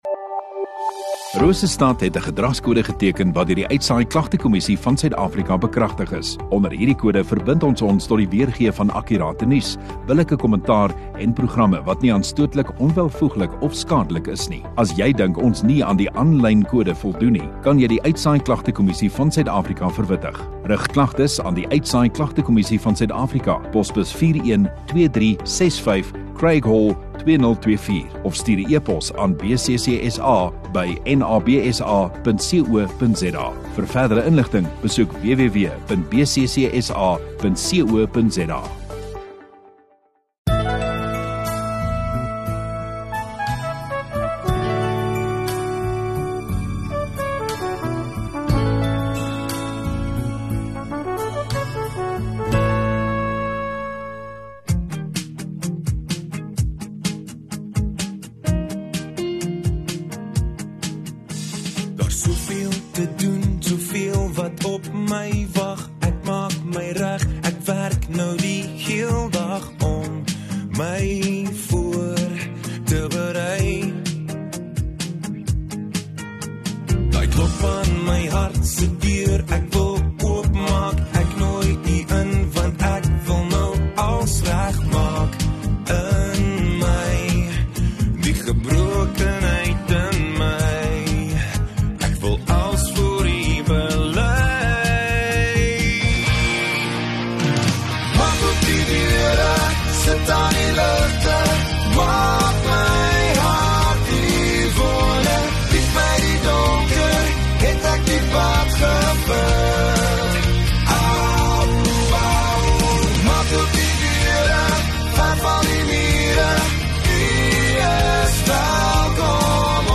13 Apr Saterdag Oggenddiens